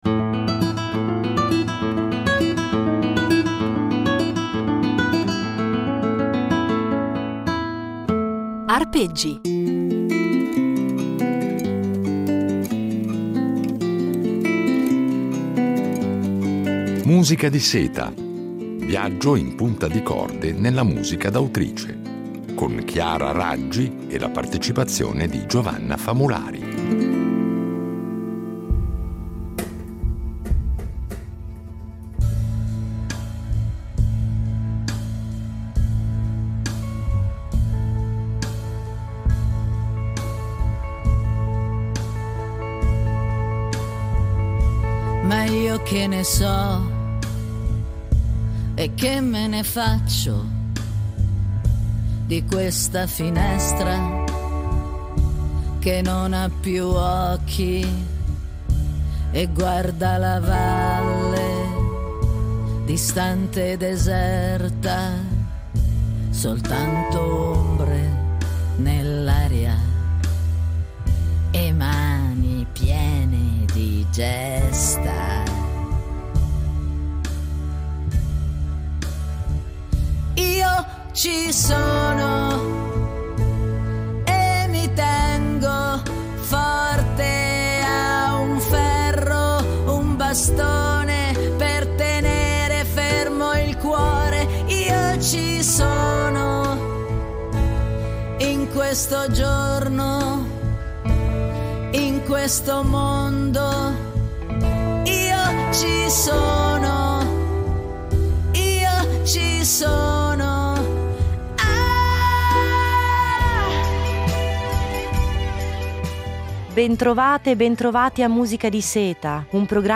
Una serie di Arpeggi impreziosita dalle riletture originali di un duo, ancora inedito, con la violoncellista